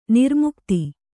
♪ nirmukti